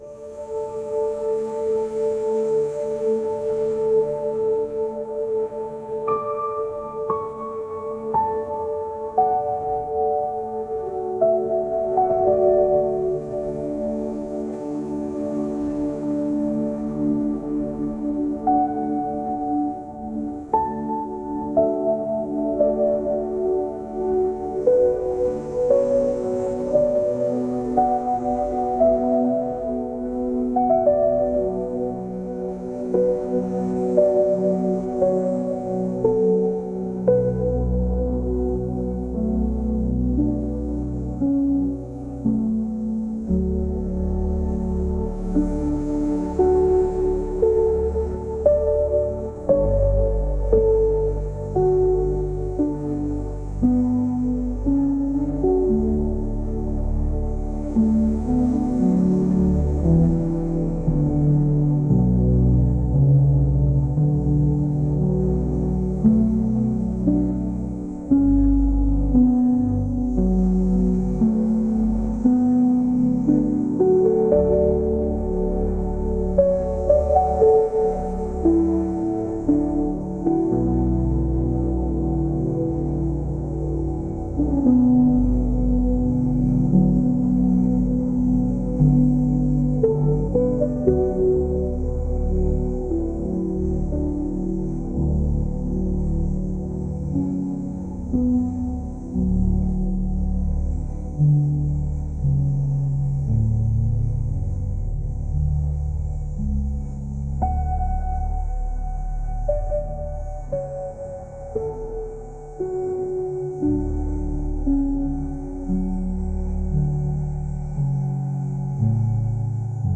暗いイメージの環境音のような曲です。